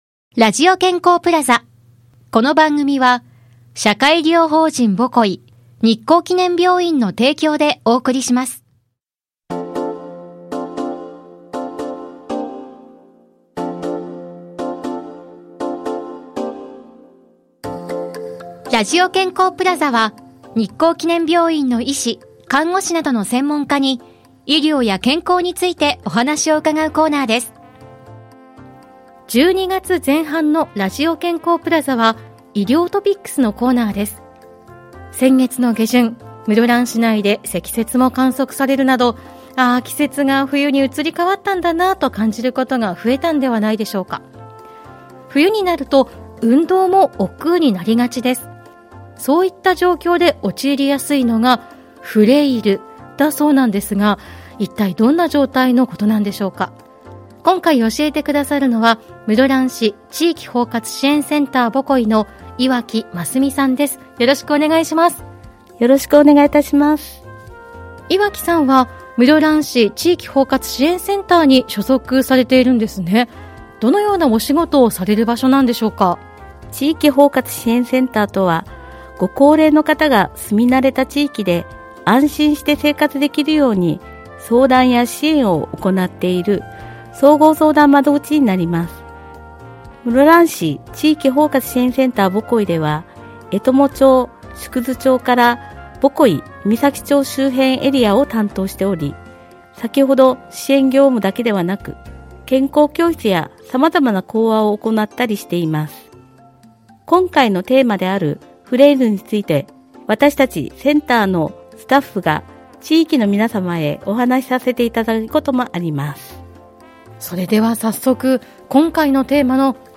室蘭市のコミュニティFM『FMびゅー』から、様々な医療専門職が登場して、医療・健康・福祉の事や病院の最新情報など幅広い情報をお届けしています。